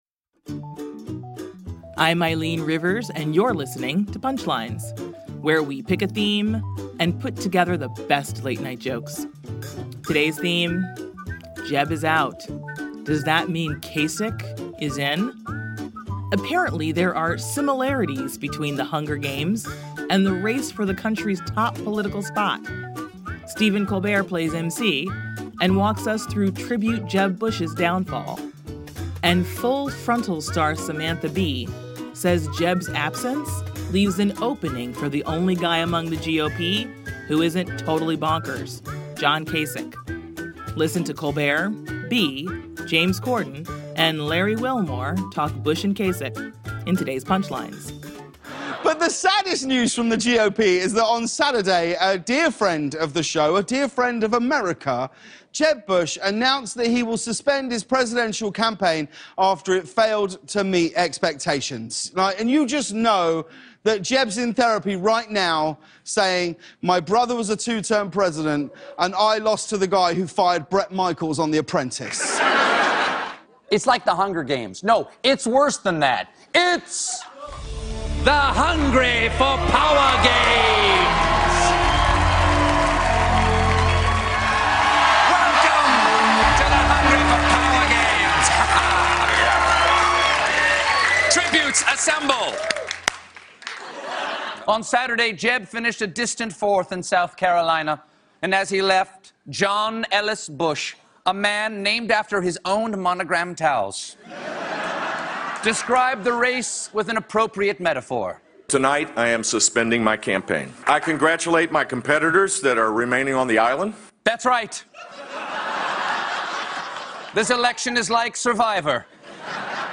The late-night comics lament the loss of Bush, and talk viable remaining candidates.